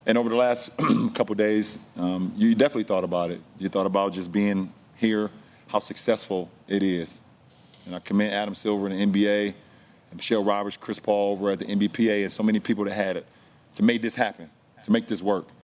James spoke about the success of the bubble this season.